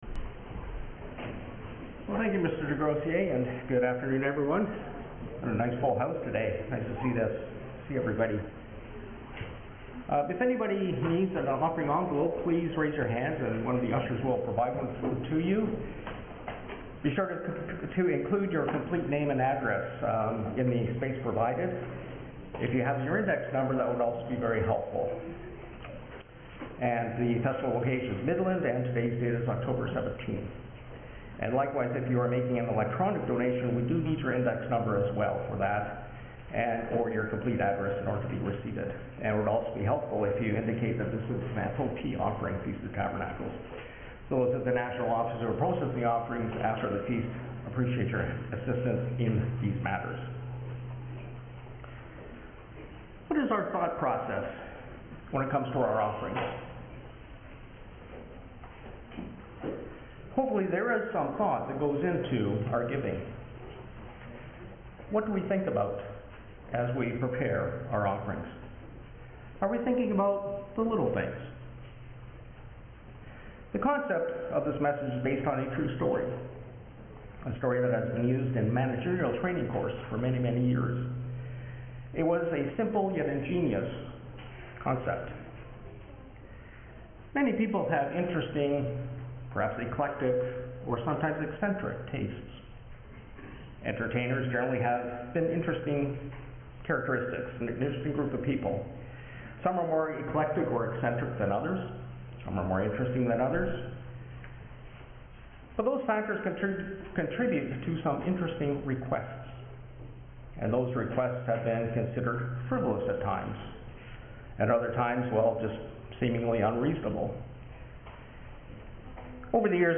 This sermon was given at the Midland, Ontario 2024 Feast site.